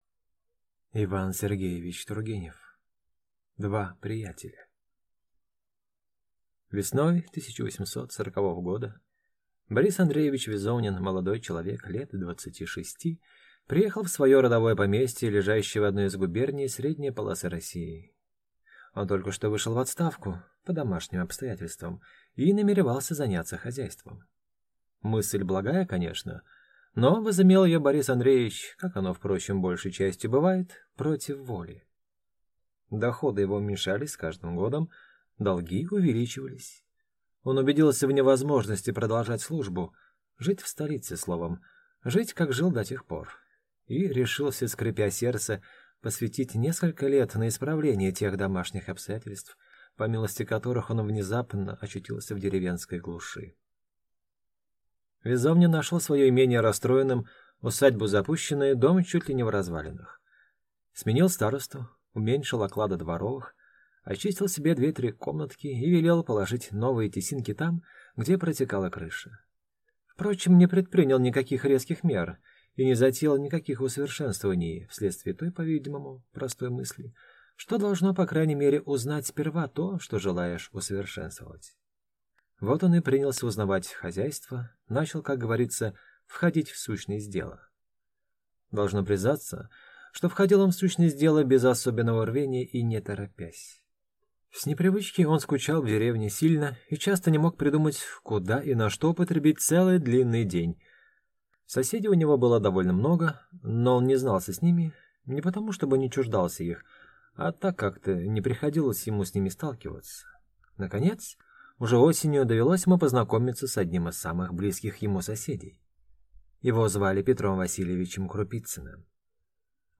Аудиокнига Два приятеля | Библиотека аудиокниг